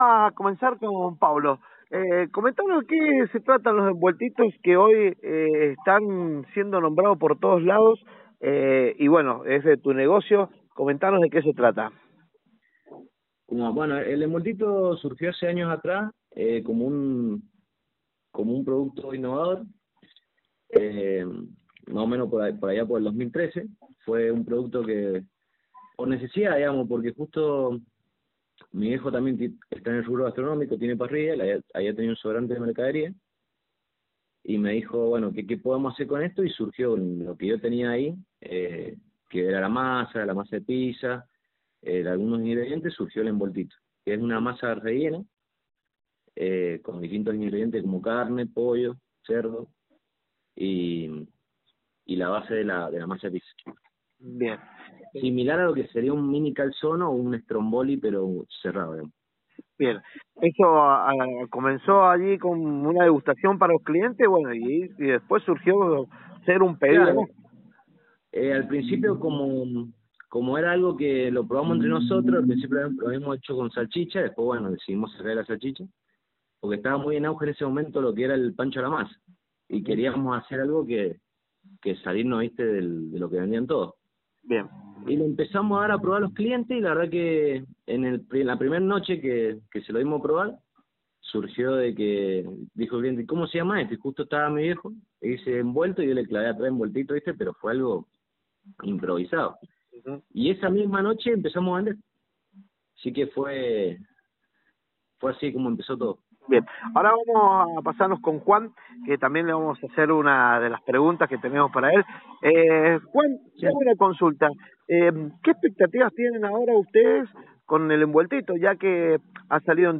Escuchamos la nota